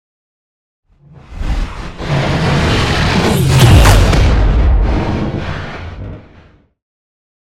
Scifi whoosh pass by shot
Sound Effects
futuristic
pass by
sci fi